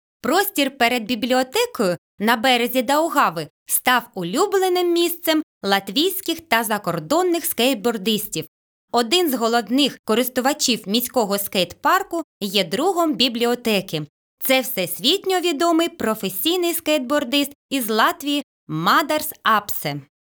Аудіогід Національної бібліотеки Латвії
balss aktieris
Tūrisma gidi
Latvijas Nacionālās bibliotēkas audio studijas ieraksti (Kolekcija)